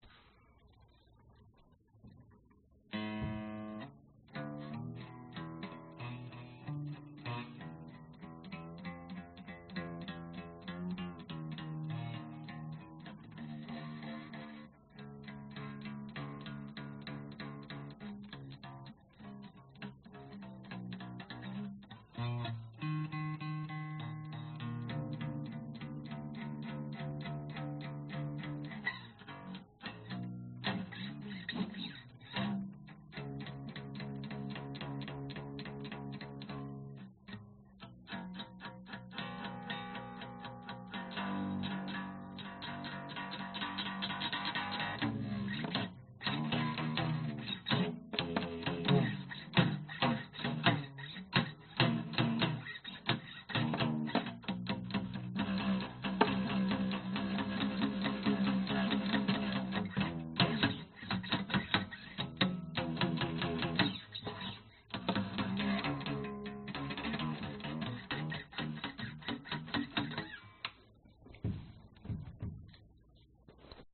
Tag: 吉他 贝斯 男声